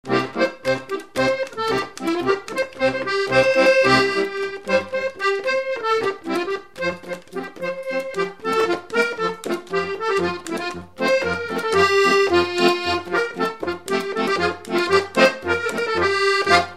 Garnache (La)
Couplets à danser
branle : courante, maraîchine
Pièce musicale inédite